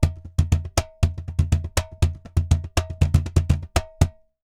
Cajon_Baion 120_1.wav